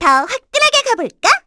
Cleo-Vox_Skill6_kr.wav